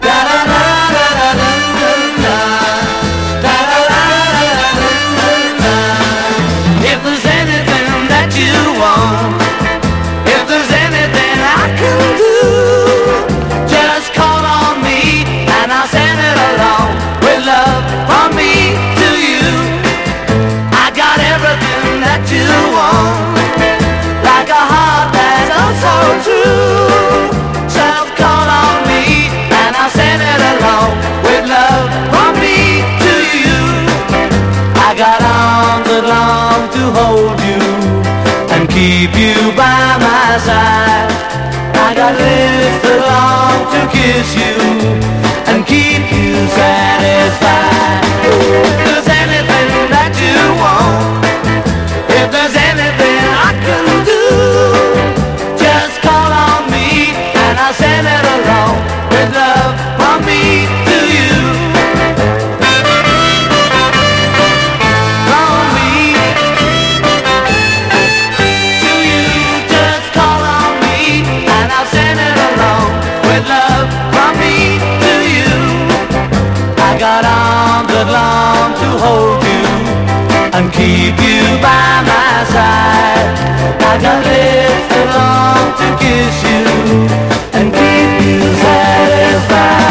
ROCK / 60'S / BRITISH BEAT
63～65年にかけてのラジオ演奏テイクをコンパイル！